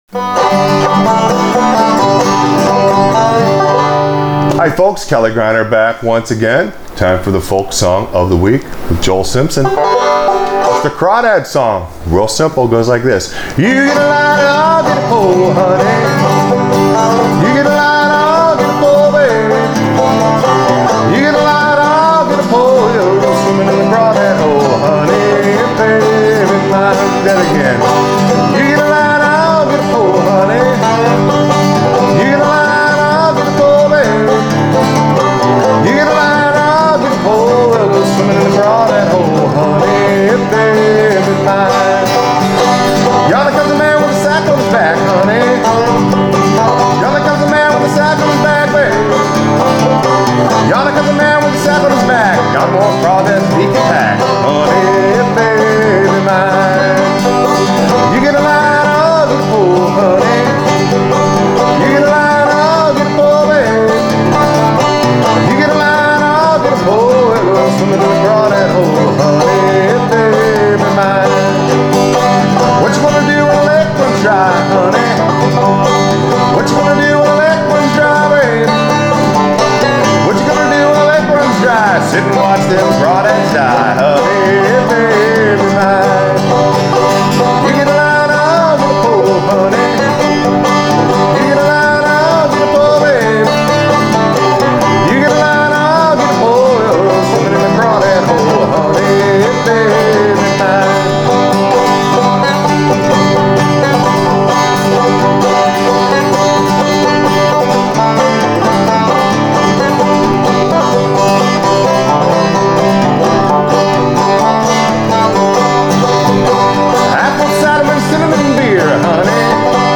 Folk Song Of The Week – Crawdad Song Accompaniment
Clawhammer BanjoFolk Song Of The WeekFrailing BanjoInstruction